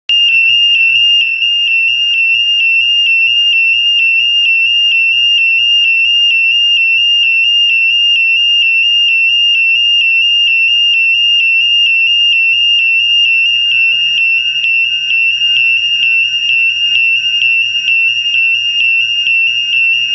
Sonido de EVACUACIÓN en Biblioteca (WMA)
sirena_bib.WMA